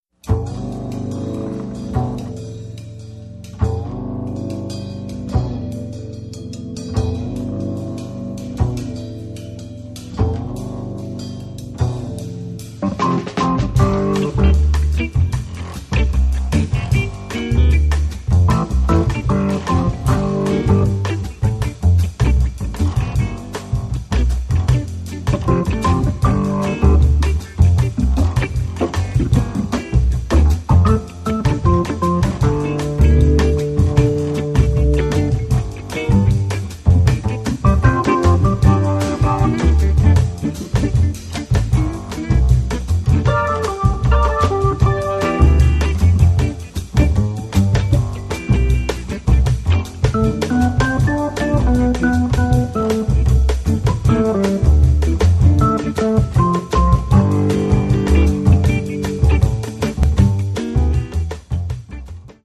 keyboards
guitar
drums
bass
rivisitazione del brano reggae